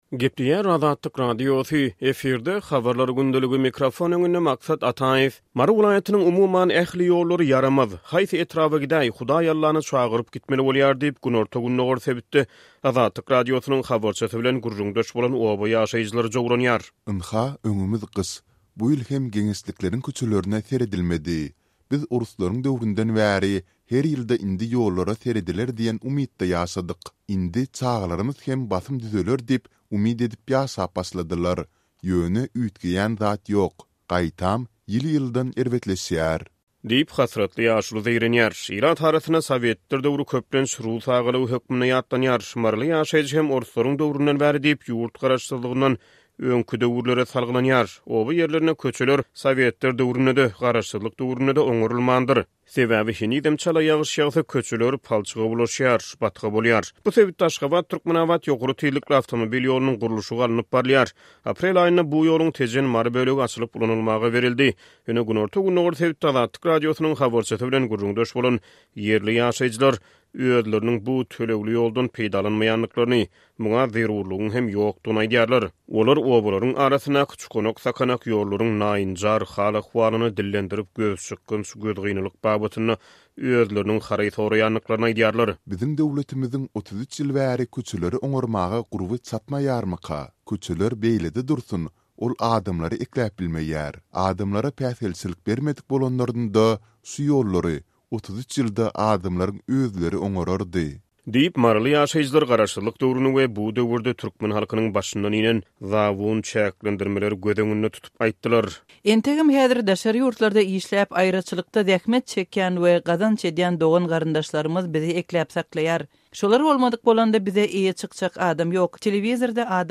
Mary welaýatynyň, umuman, ähli ýollary ýaramaz, haýsy etraba gidäý, Hudaý Allany çagyryp gitmeli bolýar diýip, günorta-gündogar sebitde Azatlyk Radiosynyň habarçysy bilen gürrüňdeş bolan oba ýaşaýjylary jowranýar.